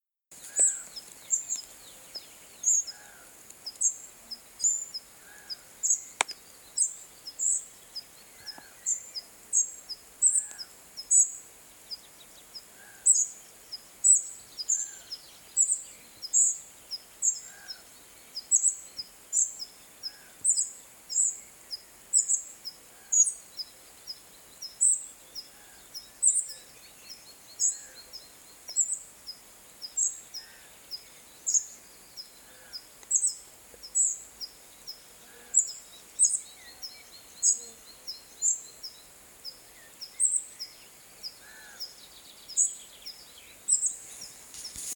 Птицы -> Мухоловковые ->
серая мухоловка, Muscicapa striata
СтатусПара в подходящем для гнездования биотопе